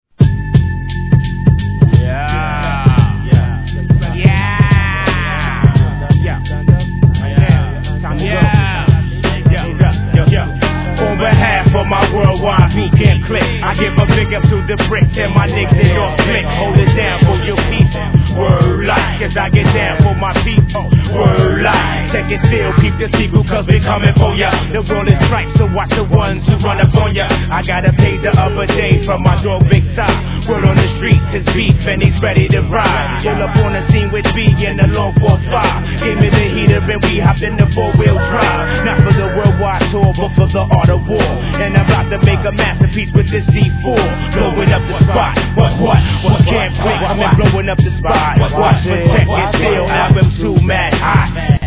HIP HOP/R&B
どれも地下臭プンプンです!